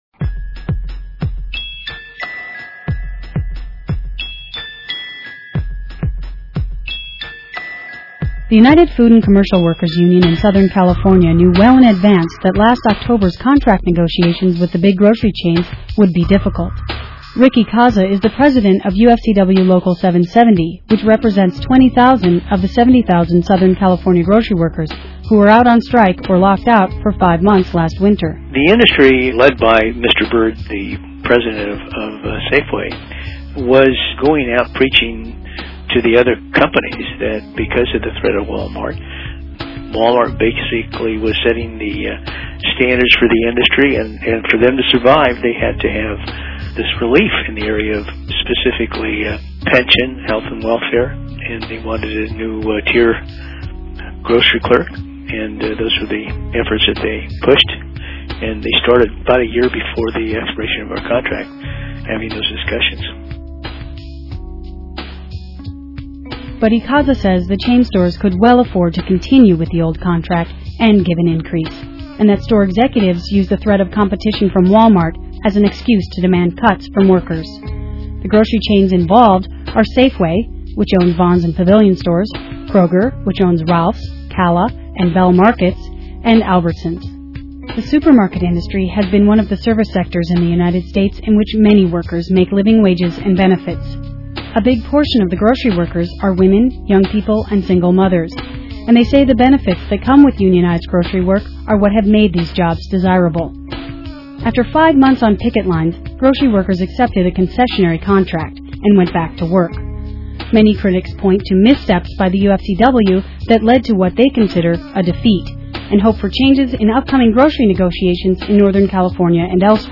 Audio Documentary of SoCal Grocery Worker Strike: A Critical Look at the UFCW Strategy. This piece aired on KPFA & KPFK on Labor Day.
Audio Documentary of SoCal Grocery Worker Strike